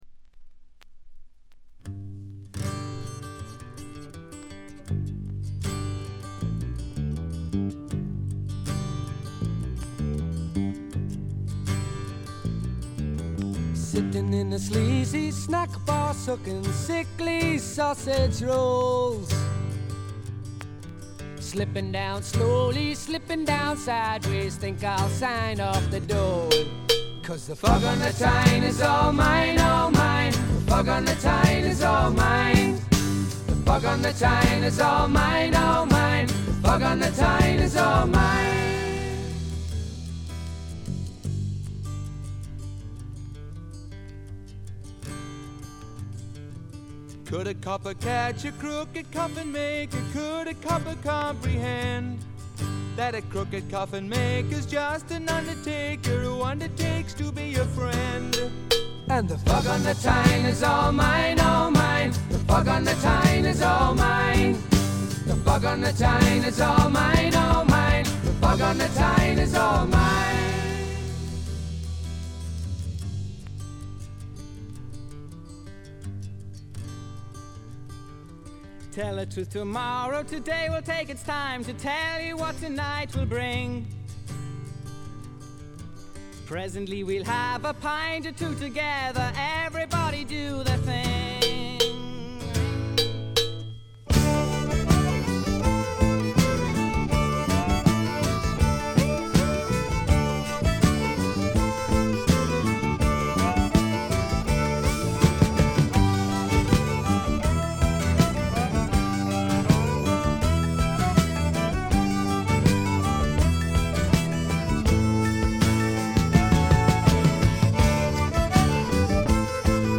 軽微なチリプチ少々。
試聴曲は現品からの取り込み音源です。